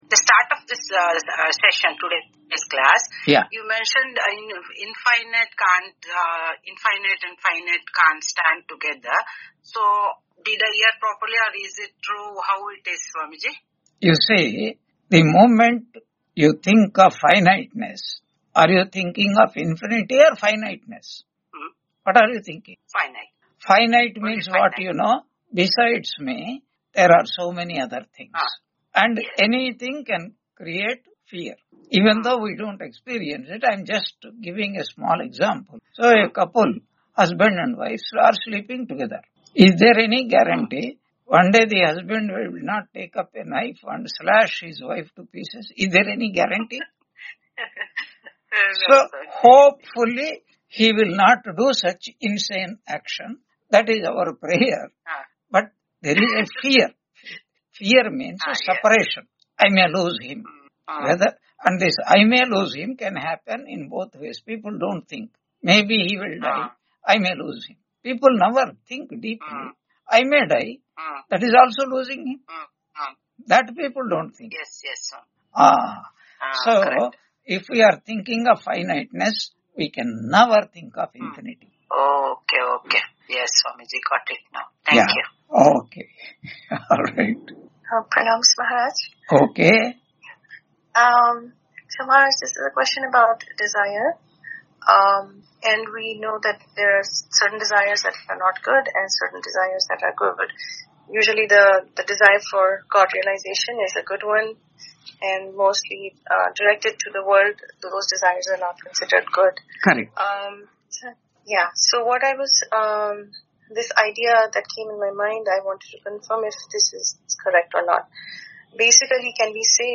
Taittiriya Upanishad Lecture 88 Ch2 7.1-3 on 21 January 2026 Q&A - Wiki Vedanta